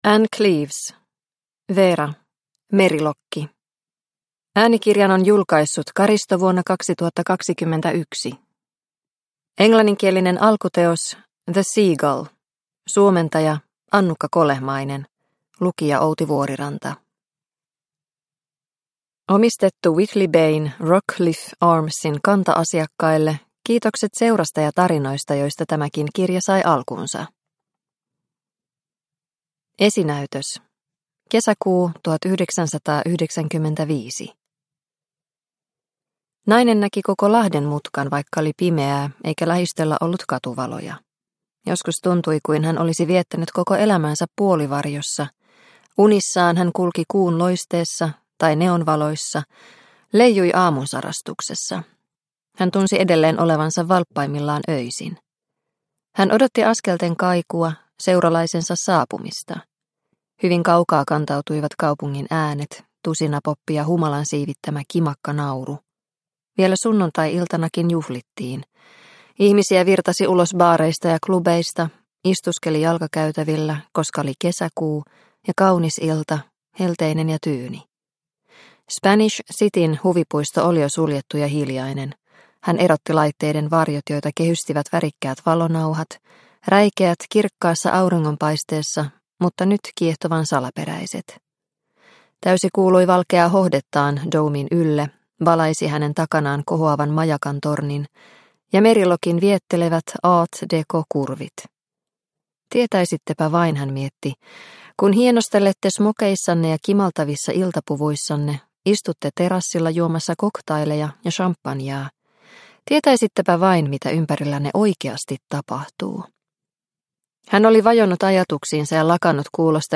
Merilokki – Ljudbok – Laddas ner